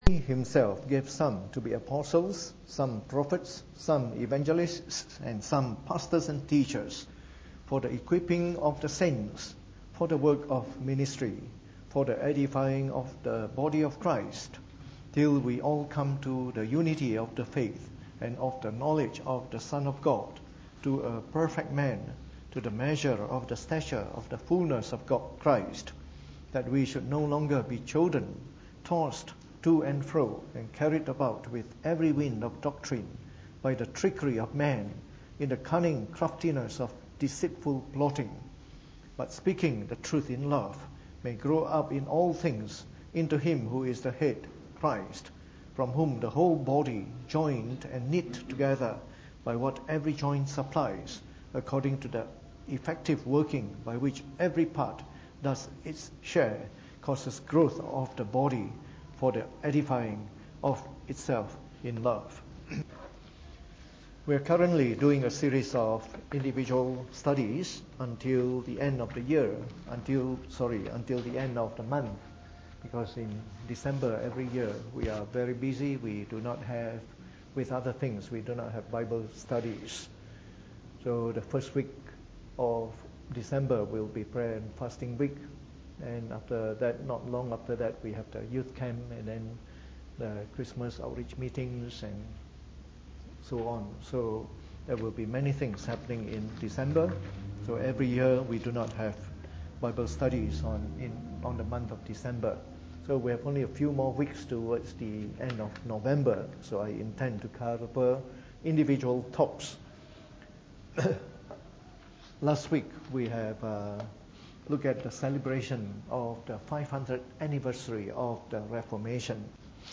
Preached on the 8th of November 2017 during the Bible Study.